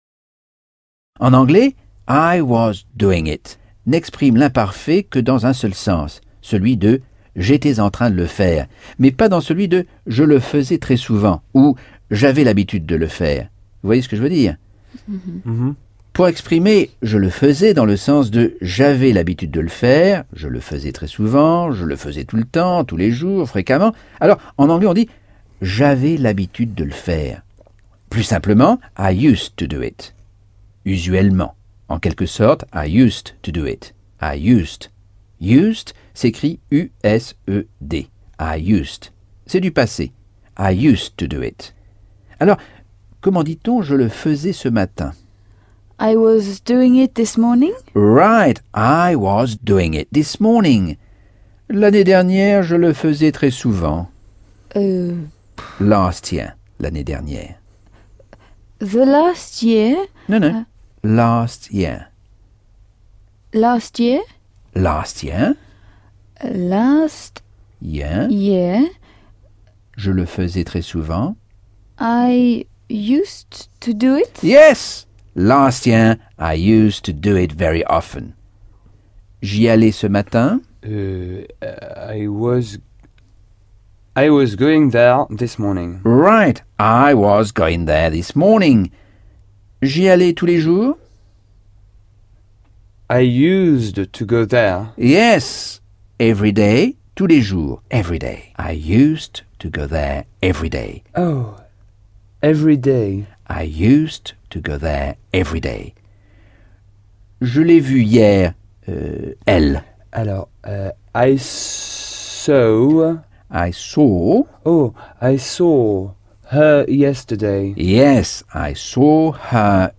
Leçon 1 - Cours audio Anglais par Michel Thomas - Chapitre 11